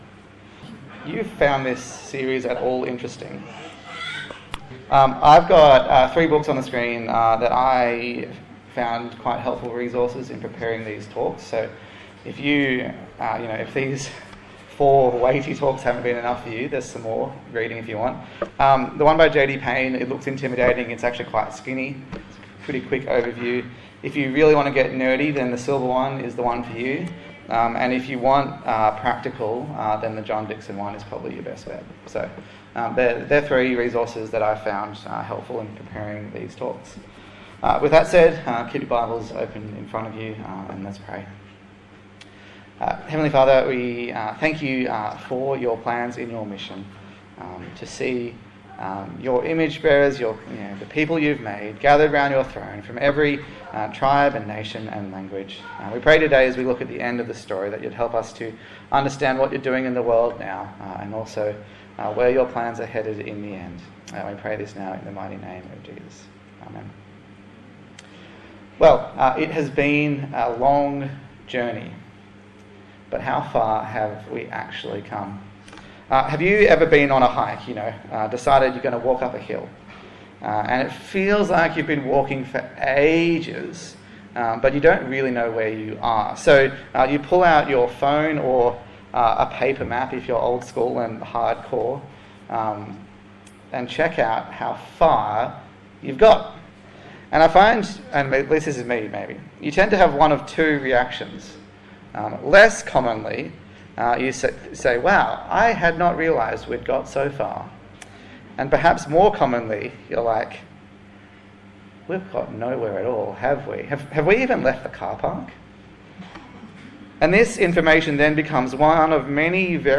God's Mission Passage: Revelation 7 Service Type: Sunday Morning